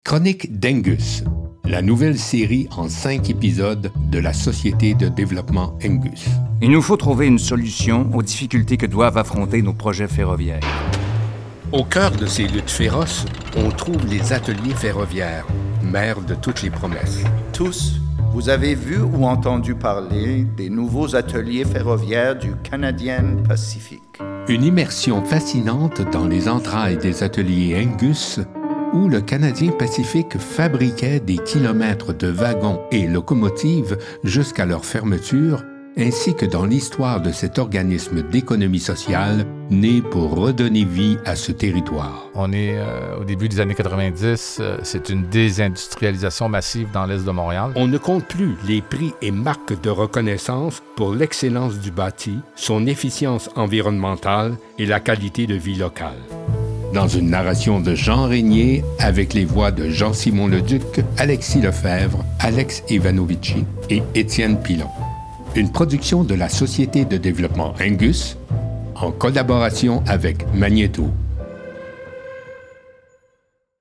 Timbre Grave - Petit grain chaleureux
Composition vocale Conviviale - Douce - Nuancée - Invitante - Humoristique
Chroniques Angus - Neutre - Captivant - Québécois soutenu /
Narration documentaire - Balado 2024 1:14 3 Mo